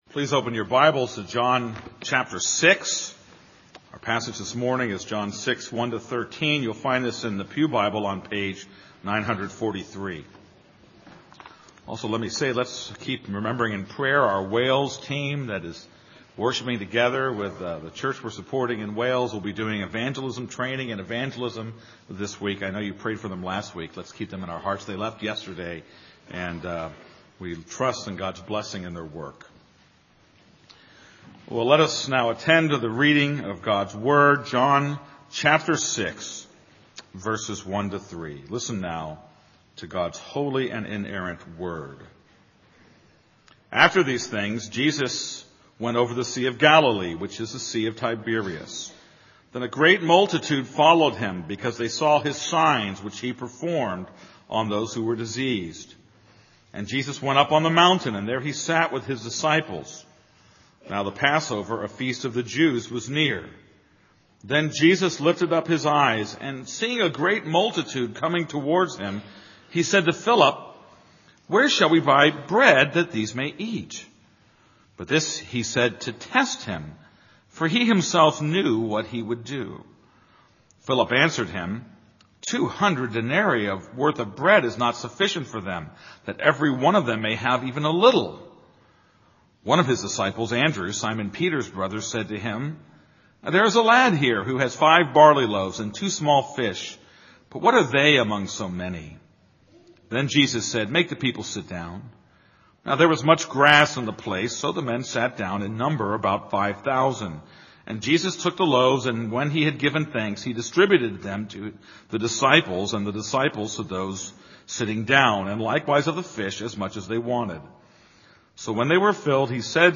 This is a sermon on John 6:1-13.